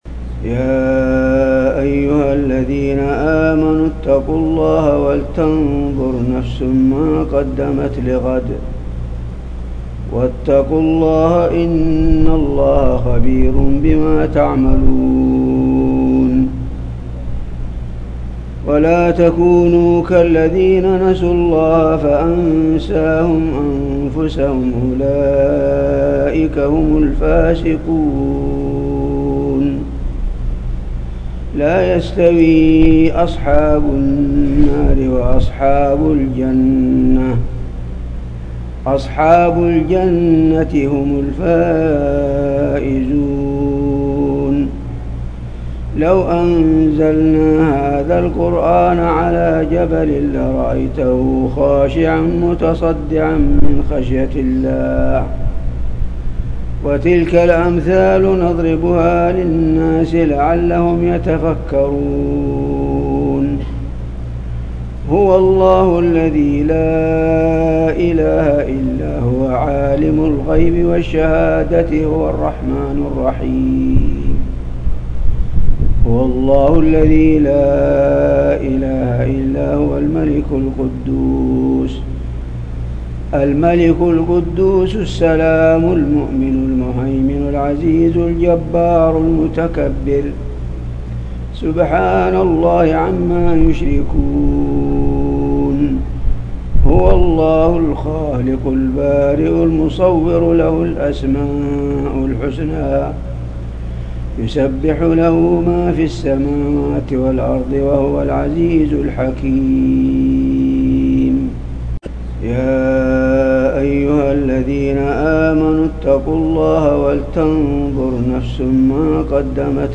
تلاوات
رواية : حفص عن عاصم